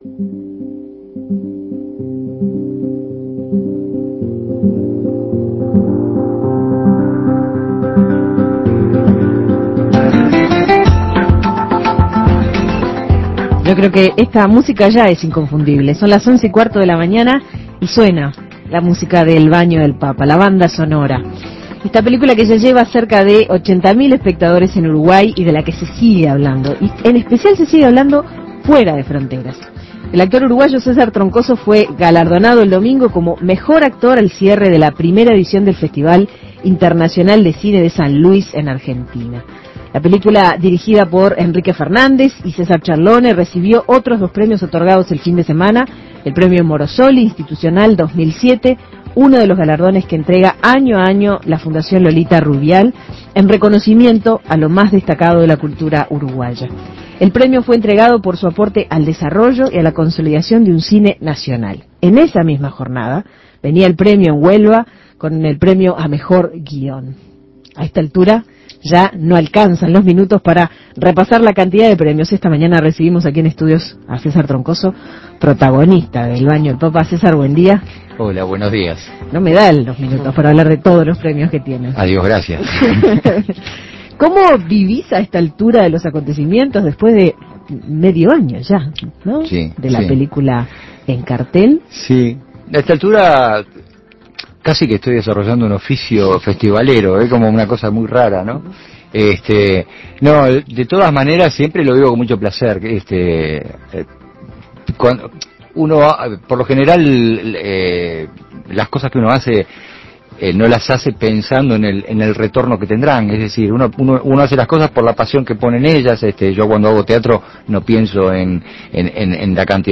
Escuche la entrevista con César Troncoso
César Trocoso, protagonista del reconocido filme uruguayo "El Baño del Papa", fue galardonado como mejor actor al cierre de la primera edición de festival internacional de cine de San Luis, en Argentina. Con respecto a este premio y a muchos otros que ganó la película, Troncoso dialogó con En Perspectiva Segunda Mañana.